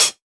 Closed Hats
Dilla Hat 25.wav